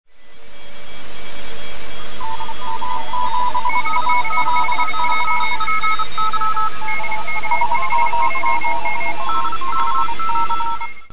If it didn’t work, then there would be little point in making all the following stages, which even if they were OK, would not do their job, because this first board hadn’t. So we stuck the lead at bottom right into a nearby standard domestic audio amplifier, just to see.
And behold: Morse signals, though very faint, could distinctly be heard.
It was a pile-up in late afternoon.